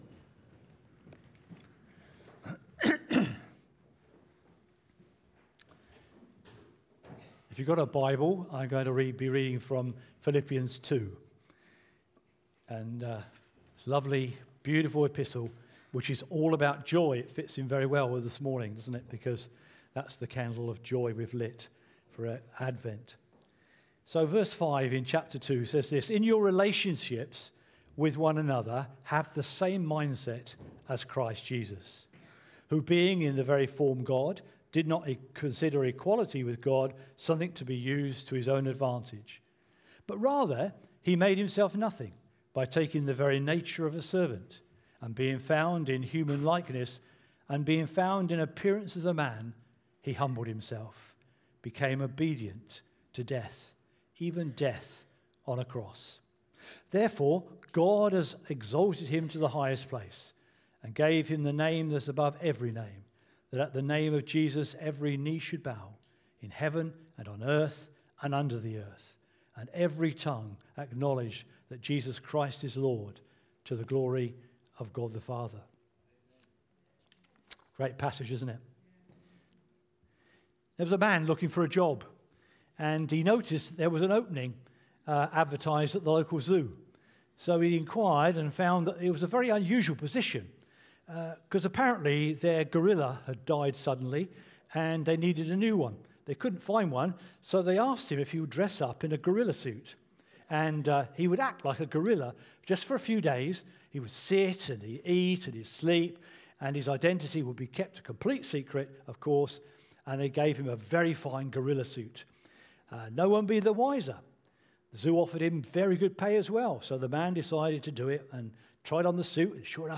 Sermons From Christchurch Baptist Church (CBCDorset)